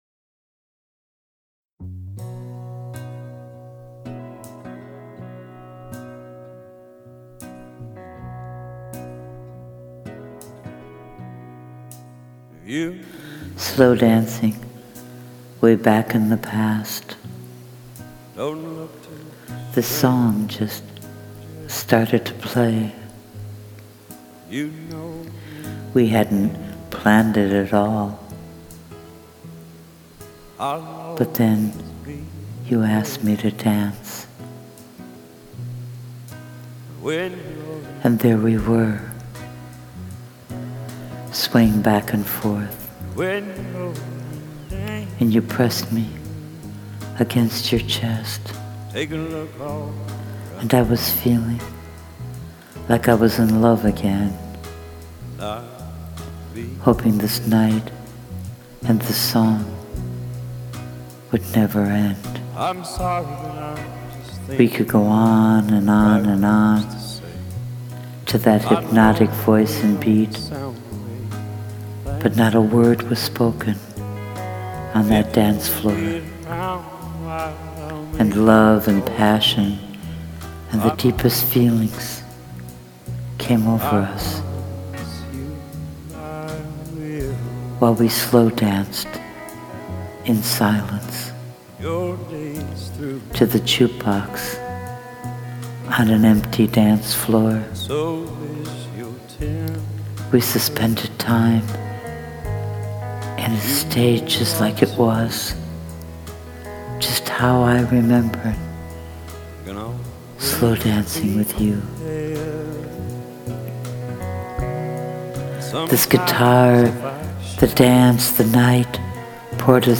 Please Click Play to hear me recite my poem to the beautiful music, The Promise by Sturgill Simpson, that inspired me to write it.
Only you could do spoken word over a track with lyrics, and have it work perfectly.